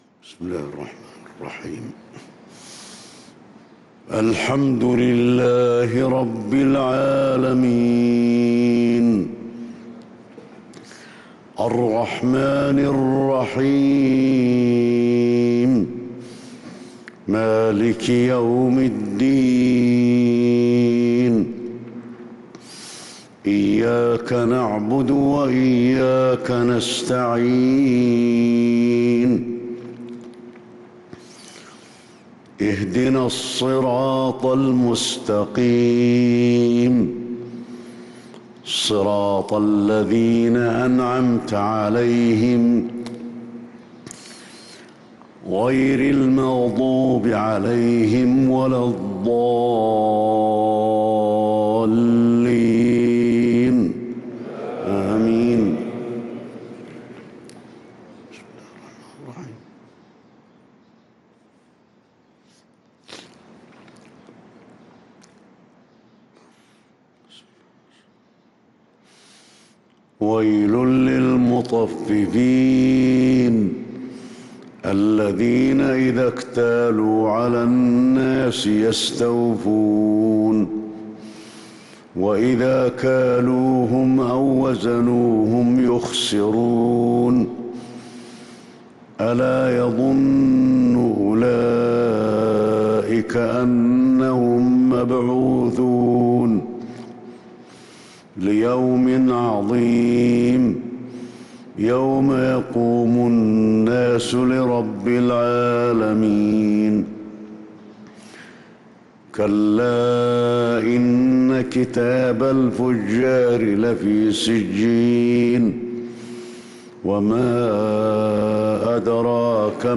فجر السبت ٦شوال ١٤٤٣هـ سورة المطففين | Fajr prayer from Surat al-Mutaffifin 7-5-2022 > 1443 🕌 > الفروض - تلاوات الحرمين